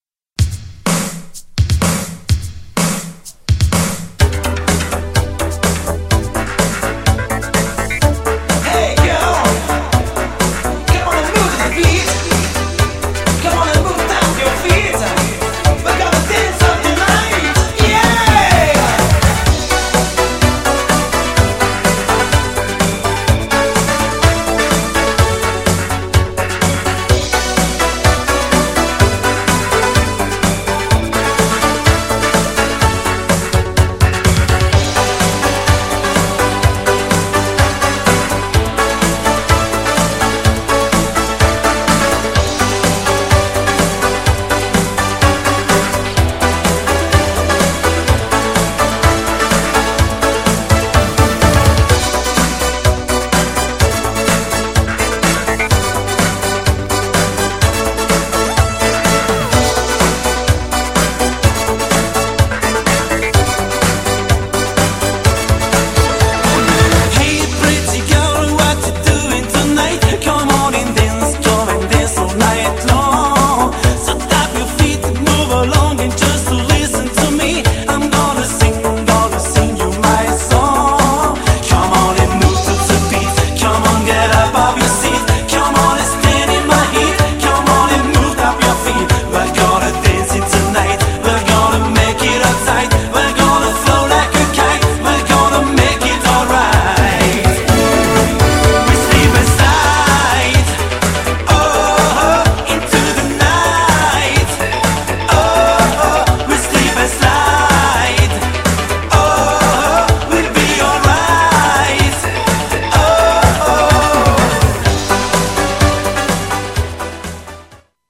GENRE Dance Classic
BPM 126〜130BPM
EURO_DISCO
ハイエナジー